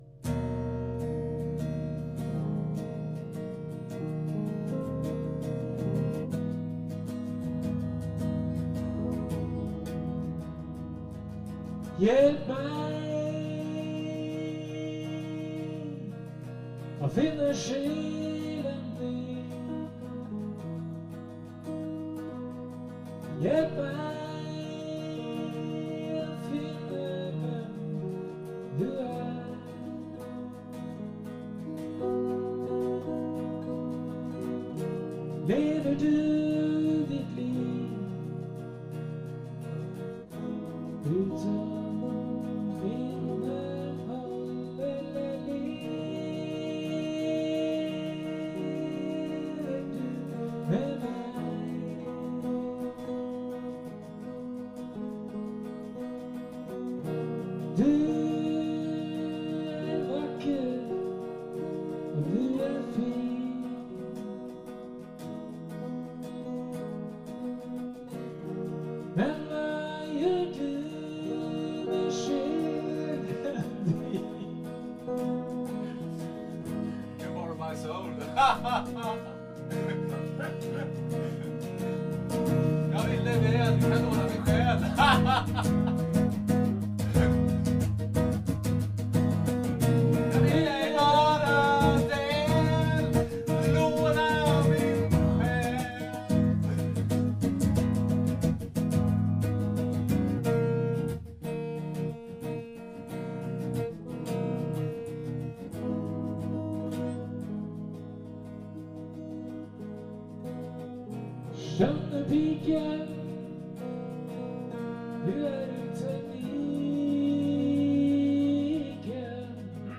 Ganska tidigt in på det nya året hade vi en tre timmars lång jam, där vi var väldigt inspirerade, och skapade mycket nytt.